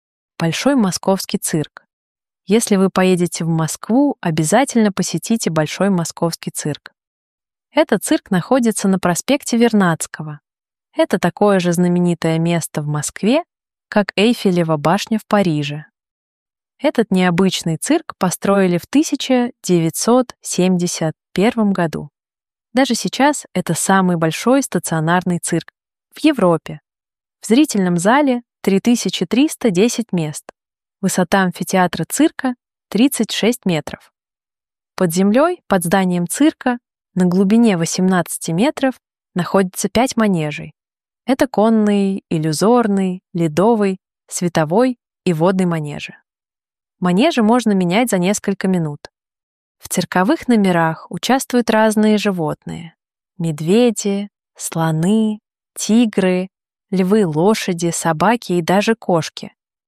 Exercice de lecture en russe : Le Grand Cirque de Moscou
exercice-lecture-russe-cirque-moscou.mp3